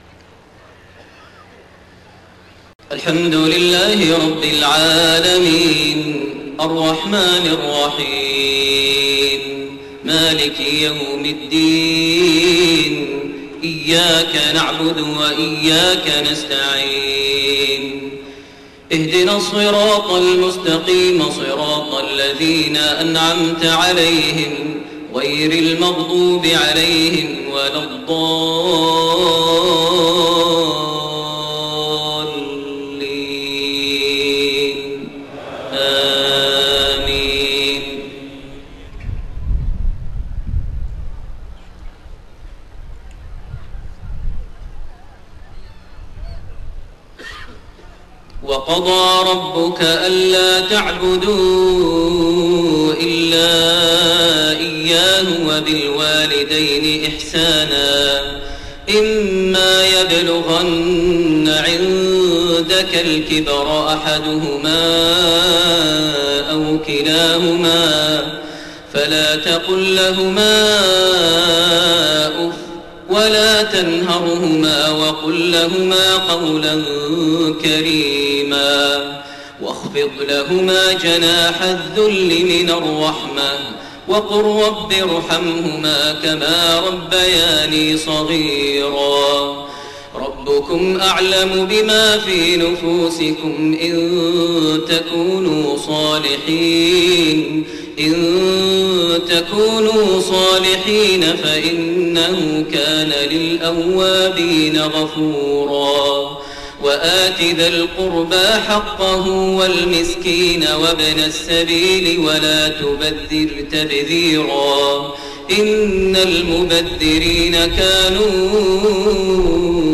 صلاة العشاء 4-5-1431 من سورة الإسراء 23-39 > 1431 هـ > الفروض - تلاوات ماهر المعيقلي